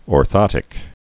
(ôr-thŏtĭk)